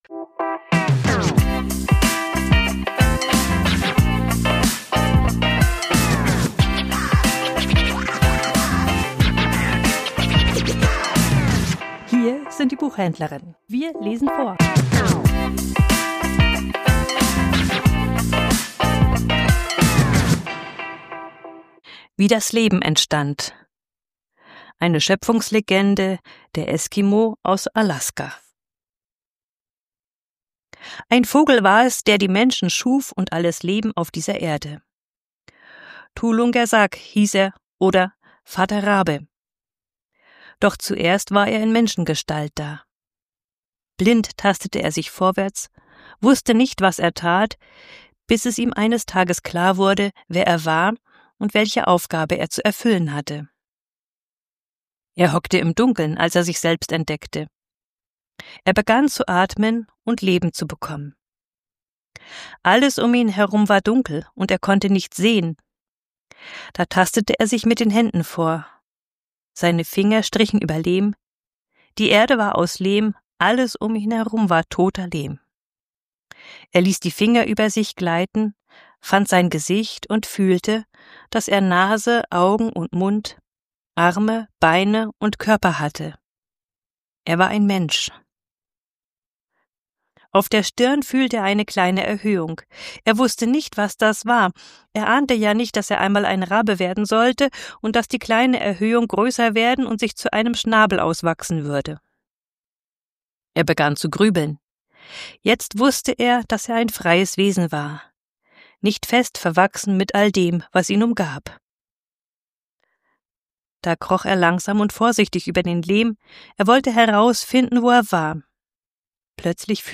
Vorgelesen: Wie das Leben entstand ~ Die Buchhändlerinnen Podcast